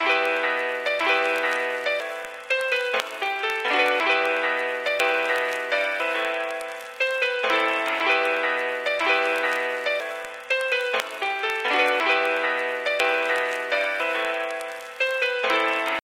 狡猾的Boom Bap钢琴
标签： 120 bpm Hip Hop Loops Piano Loops 2.70 MB wav Key : Unknown
声道立体声